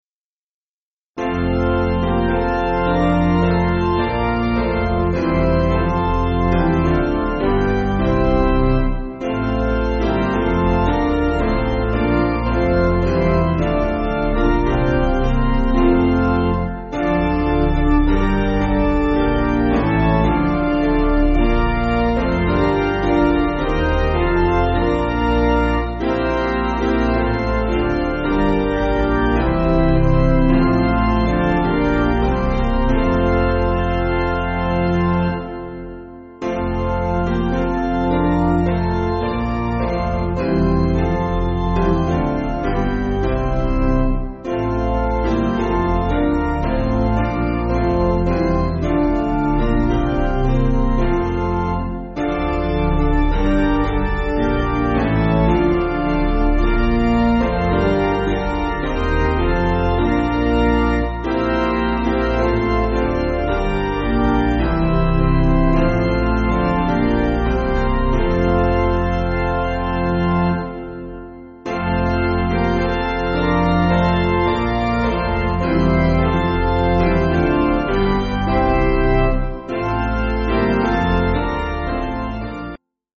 Basic Piano & Organ